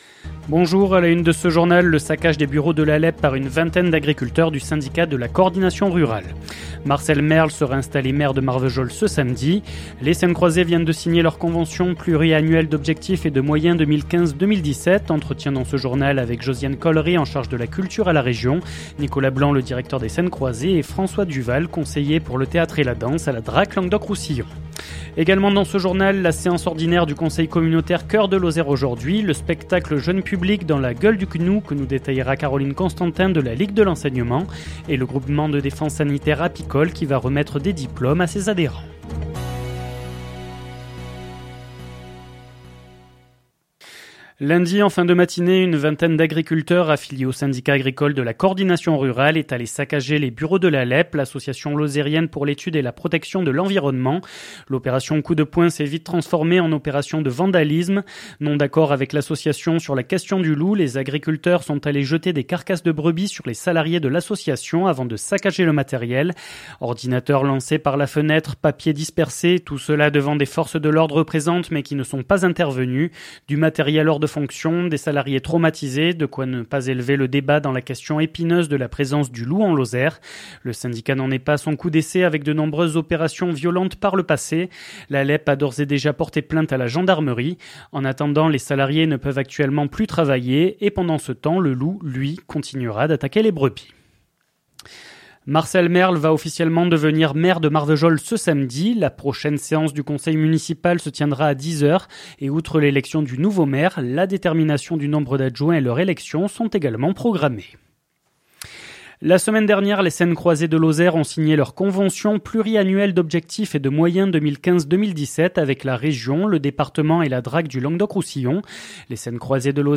Les informations locales